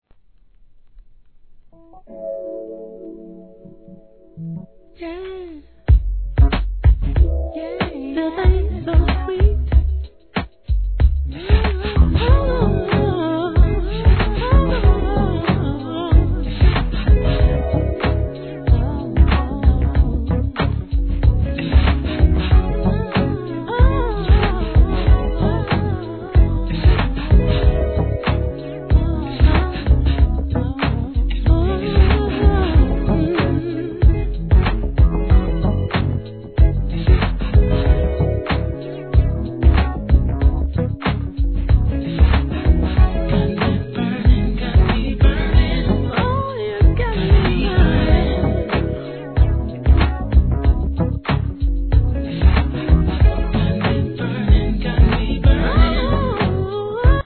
HIP HOP/R&B
底抜けな爽快感を感じさせるアップテンポ・ナンバーで陽気に踊れます♪ UK